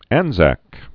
(ănzăk)